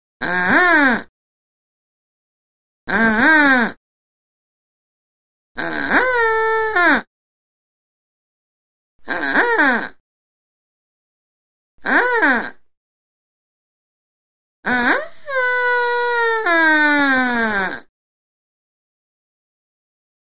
Duck Calls - 6 Effects; Long Duck Calls.